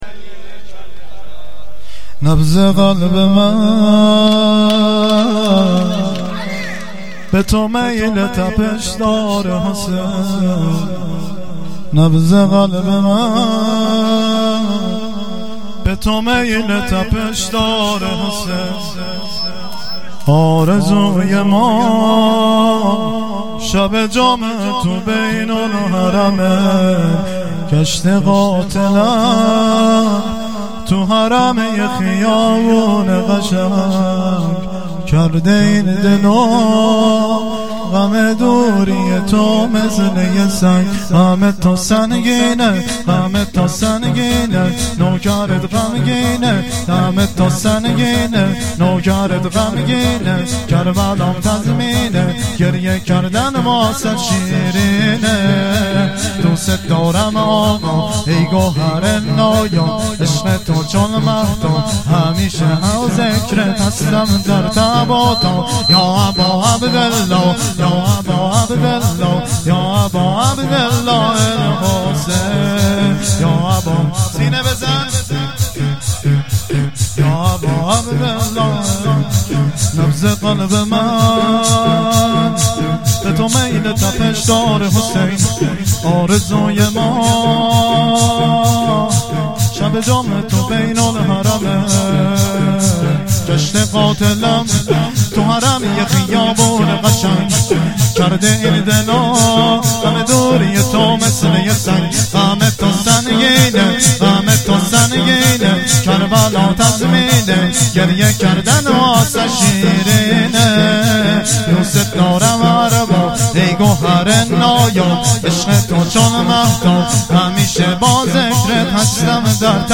مراسم هفتگی ۳۰ آبان هییت عاشقان ثارالله اشتراک برای ارسال نظر وارد شوید و یا ثبت نام کنید .